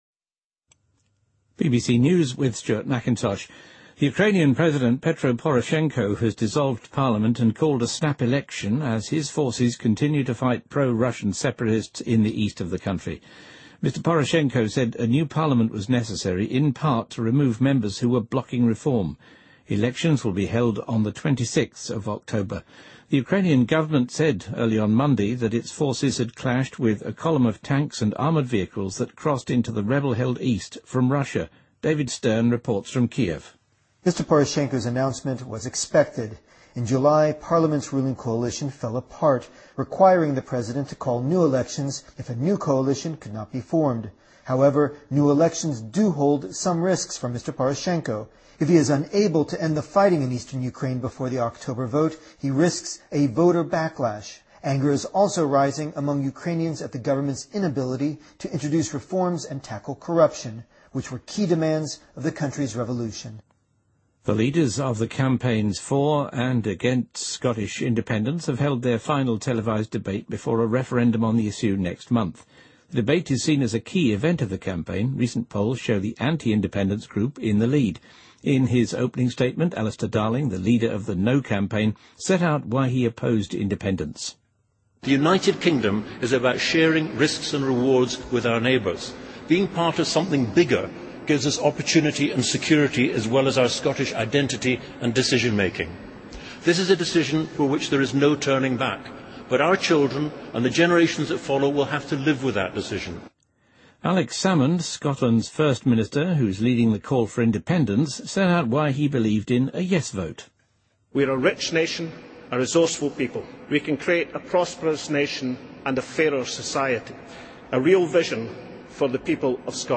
BBC news,乌克兰总统解散议会并要求进行重新选举